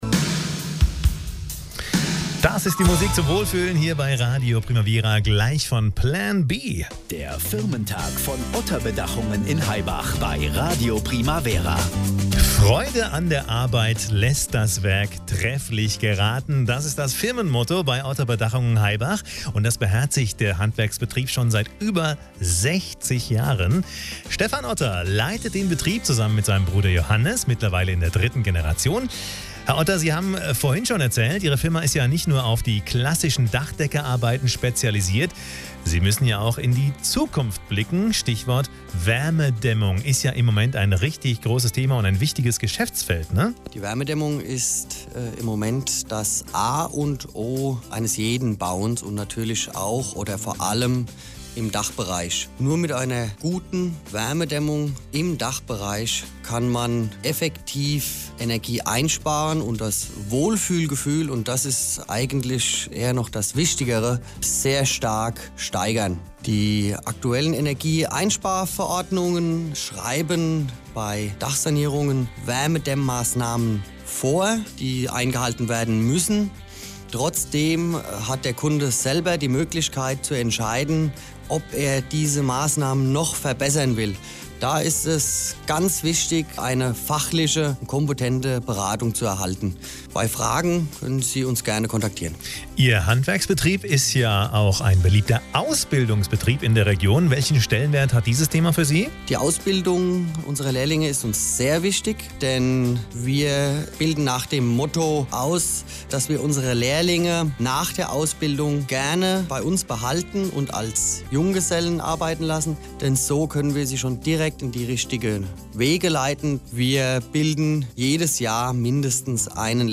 Interview beim Firmentag von Radio Primavera